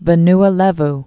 (və-nə lĕv)